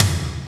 TOM FAT T06L.wav